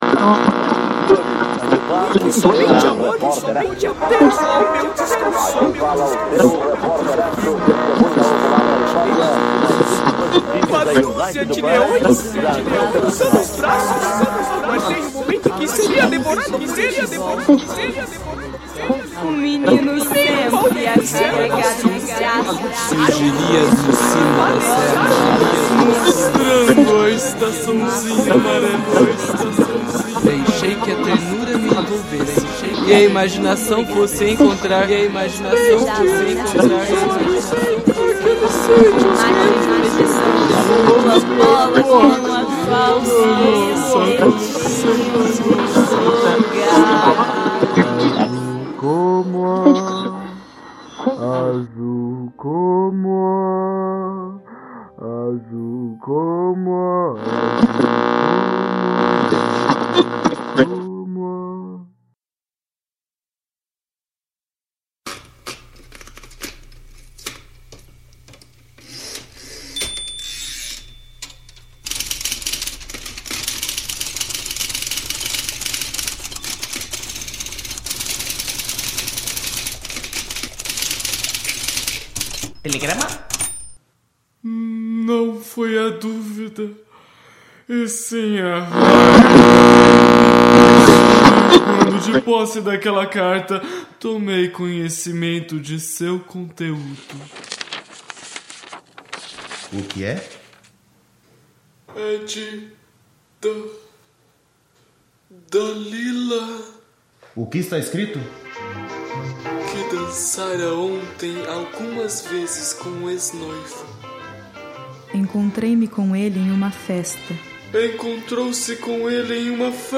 As Rubianadas - peça radiofônica